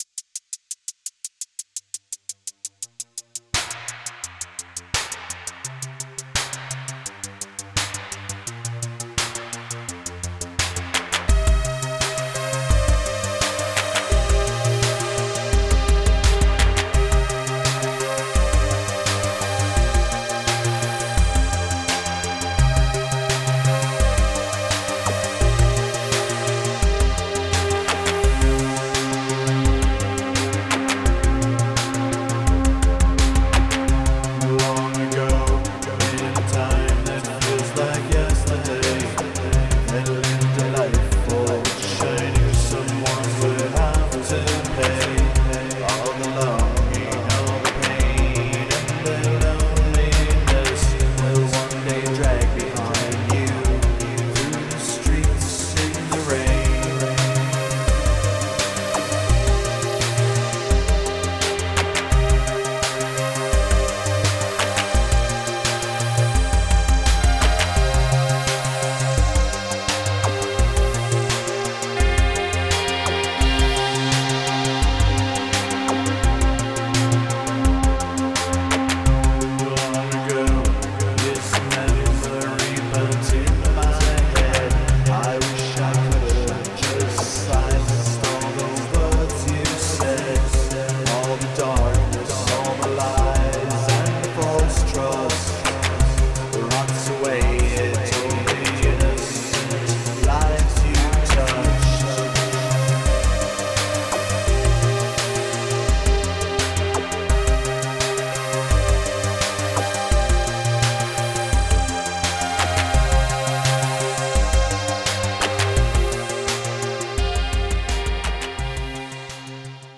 cold synth